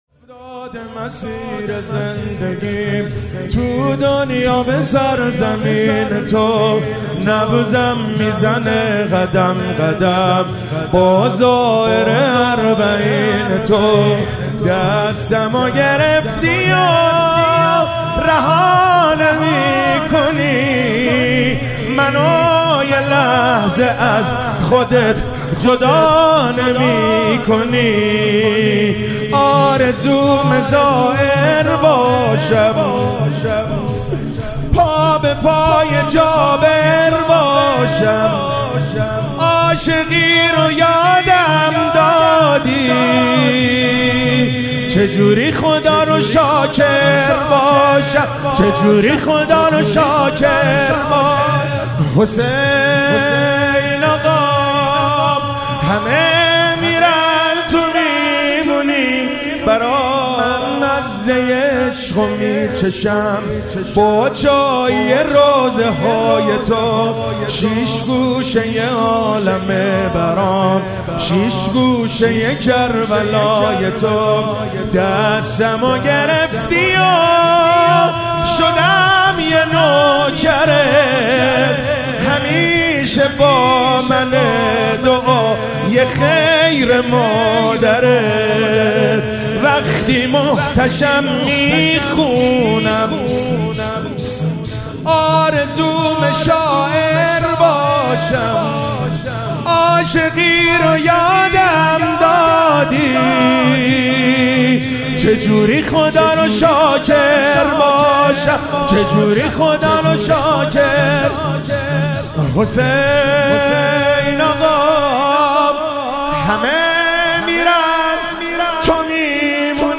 موکب الشهدا ساوجبلاغ